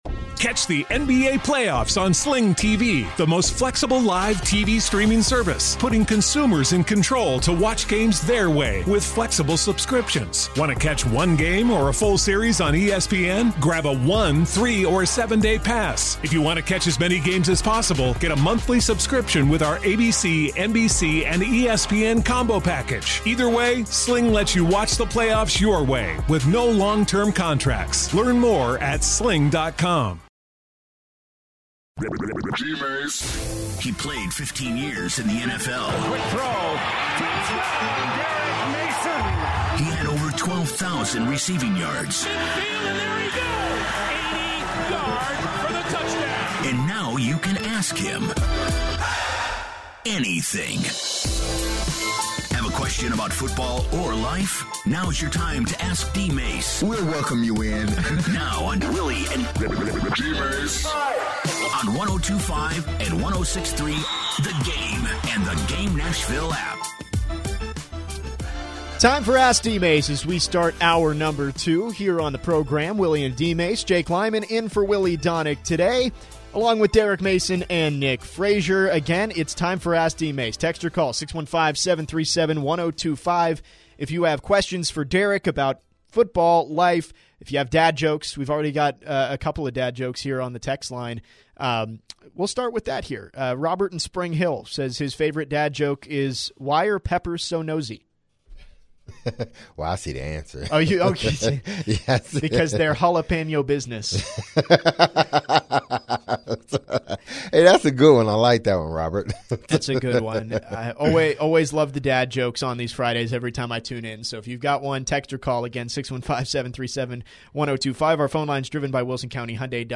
the guys answered calls and texts